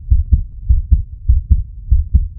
heartbeat.wav